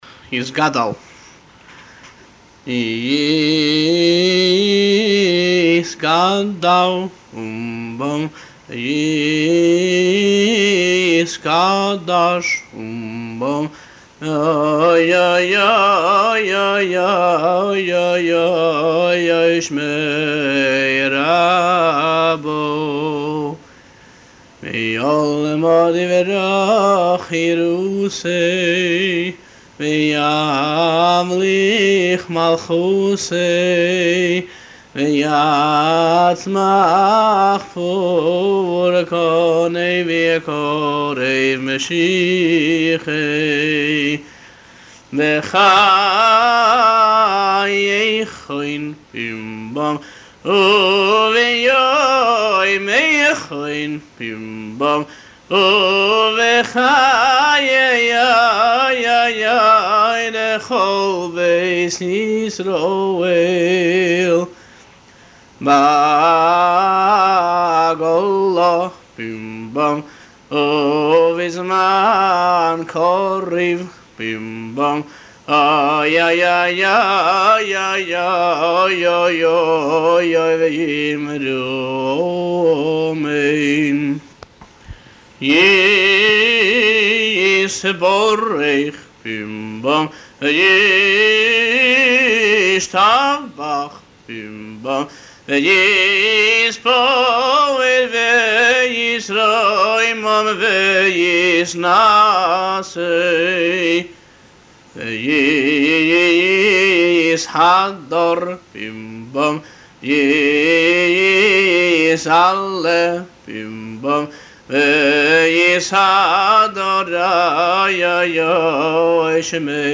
אודות הניגון [ עריכה | עריכת קוד מקור ] הניגון הוא חלק מניגוני חב"ד לתפילות שבת ויום טוב. עיקר הניגון הוא הלחן של הנוסח, וההתאמה למילות הקדיש אינה בדווקא [ 1 ] . כל שורה בניגון מחולקת ל-3 מקטעים, שחוזרים במנגינה דומה שוב ושוב.